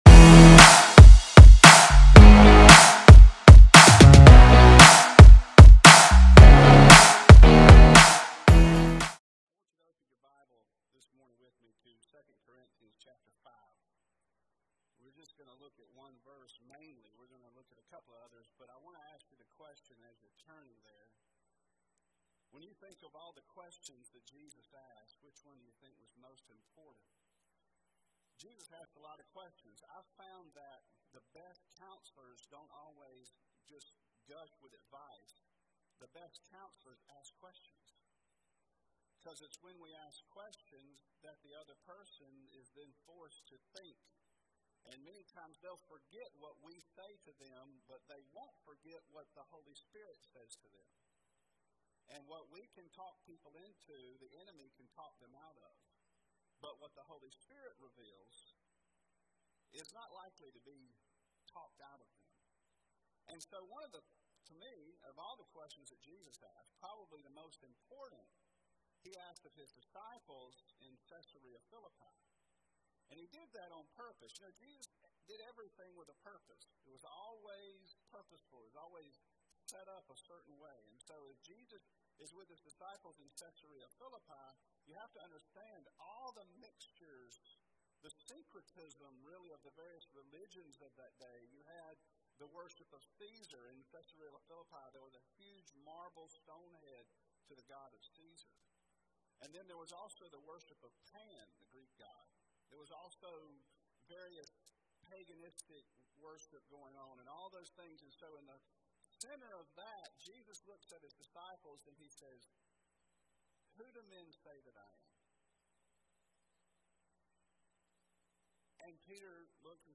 Sermons | More 2 Life Ministries